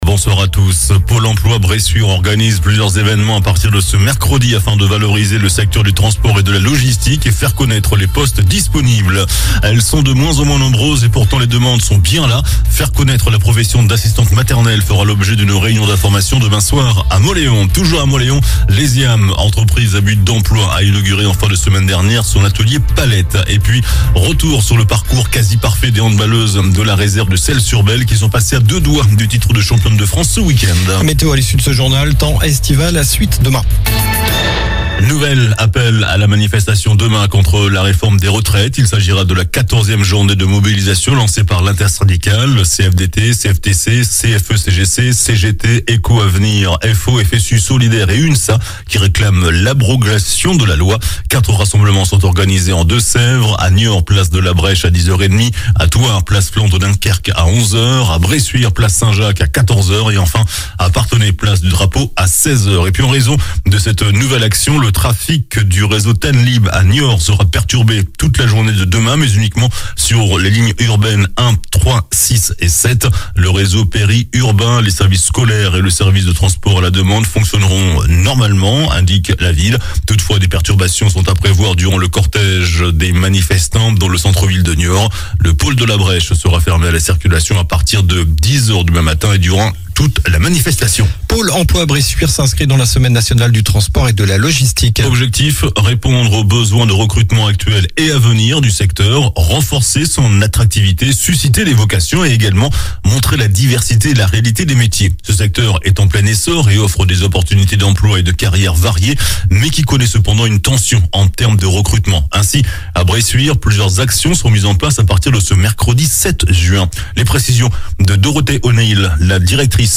JOURNAL DU LUNDI 05 JUIN ( SOIR )